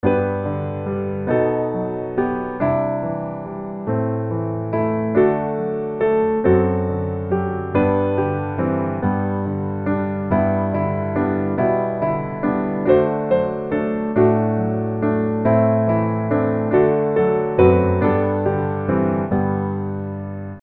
Gospel
G Majeur